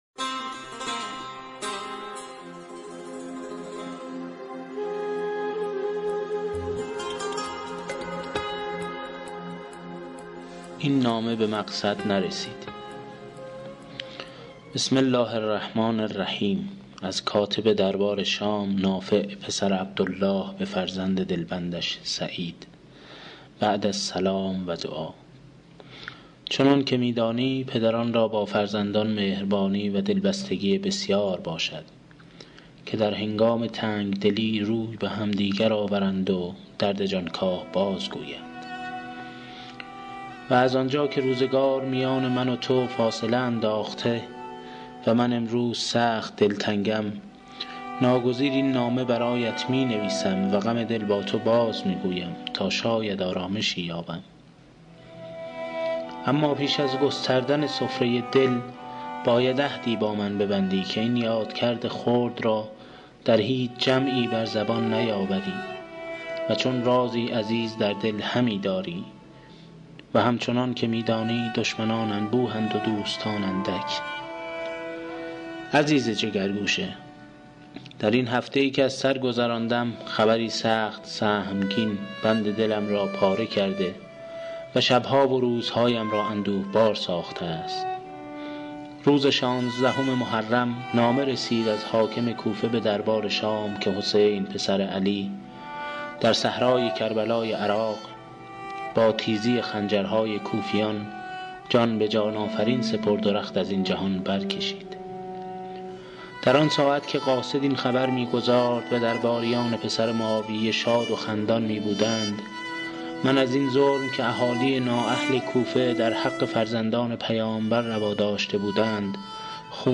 داستان‌خوانی